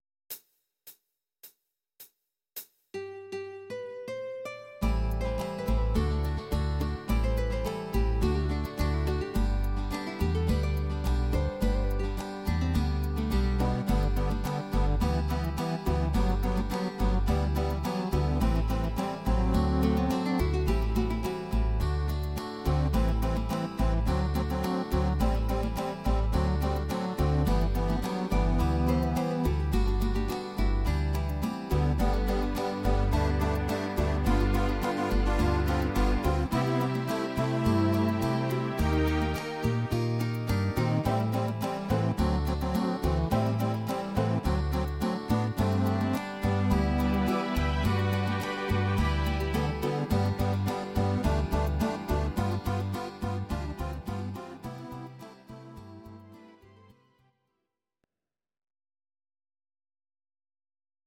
Audio Recordings based on Midi-files
Pop, Duets, 2000s